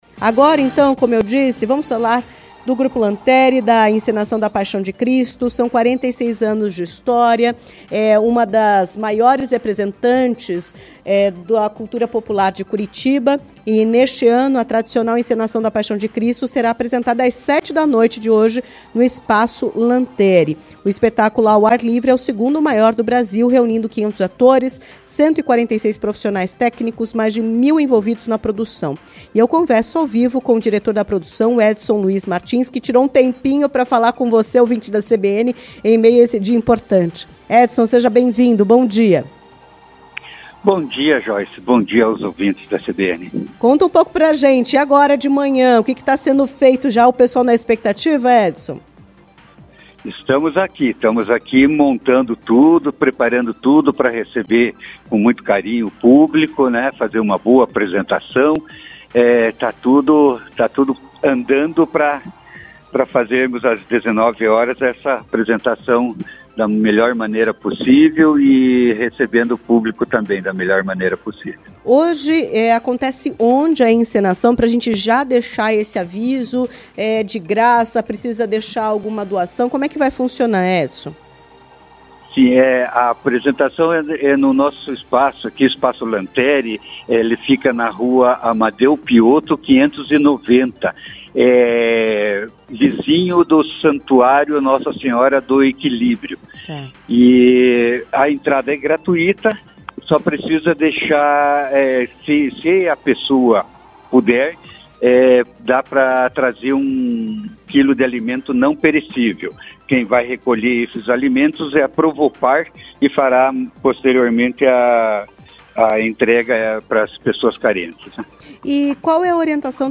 ENTREVISTA-18-04.mp3